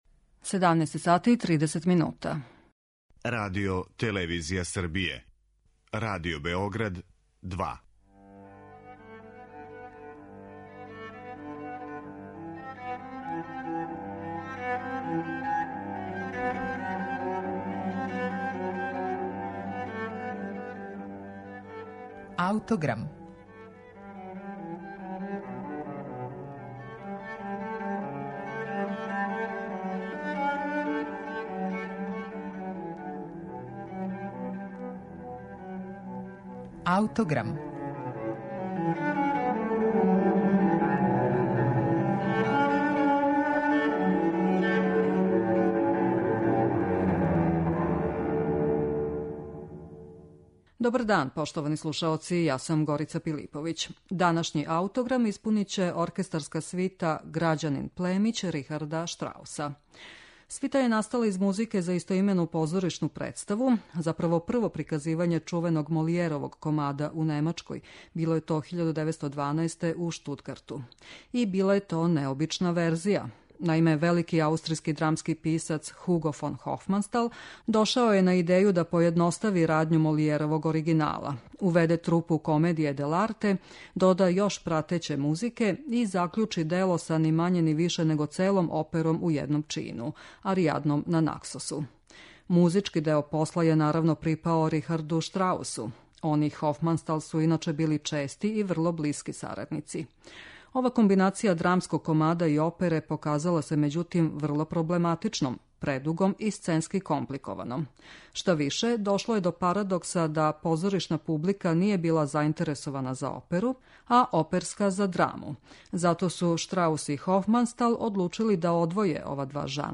Оркестарска свита 'Грађанин-племић'